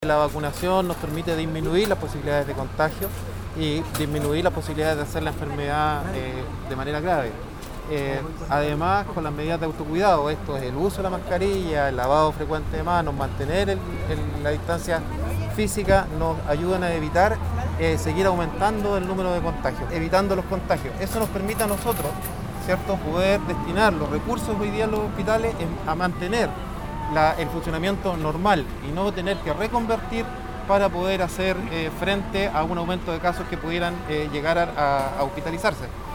A su vez, el director del servicio de Salud Chiloé José Cárdenas, reiteró que una adecuada conducta en favor de la vacunación permite dirigir de manera óptima los recursos en materia sanitaria.